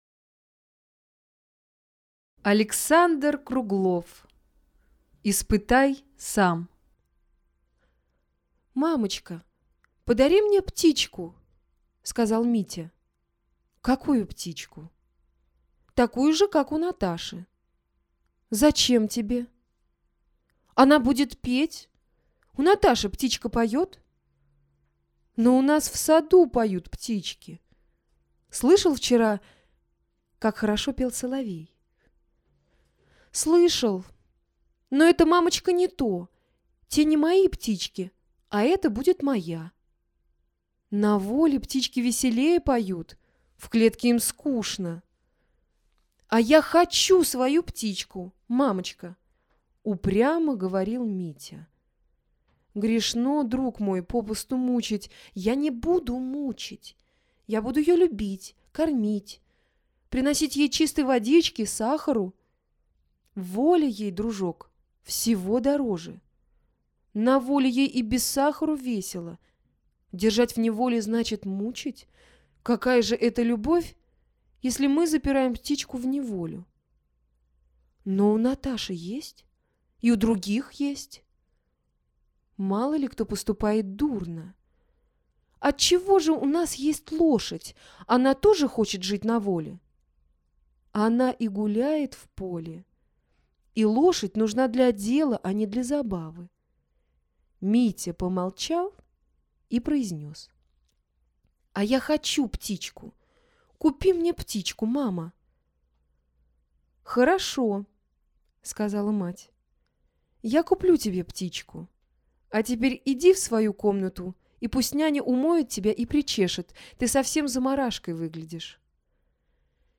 Доброе дело №1 - сборник детских рассказов